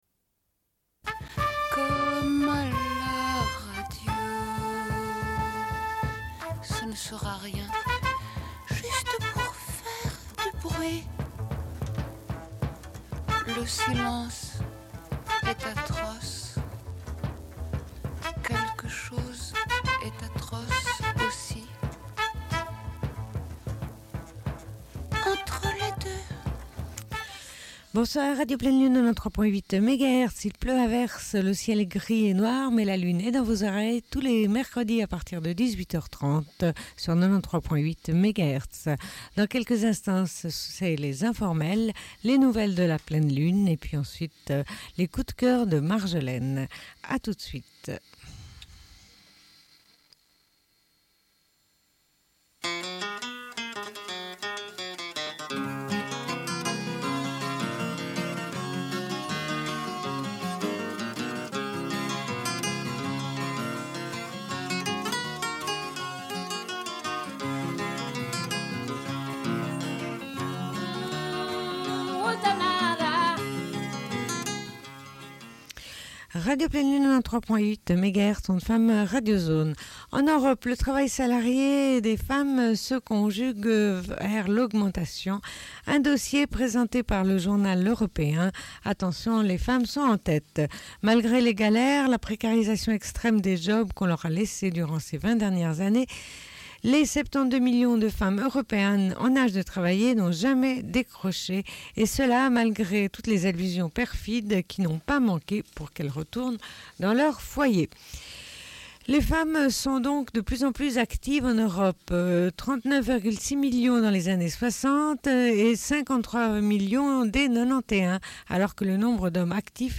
Bulletin d'information de Radio Pleine Lune
Une cassette audio, face B